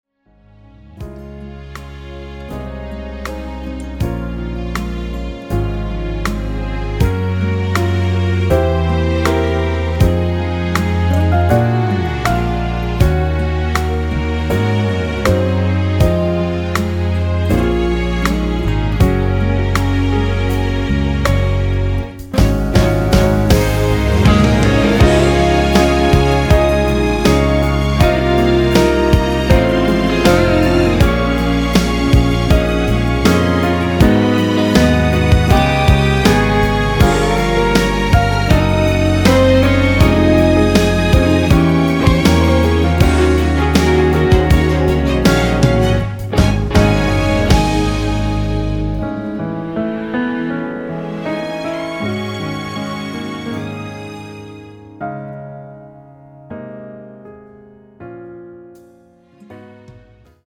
음정 원키
장르 가요 구분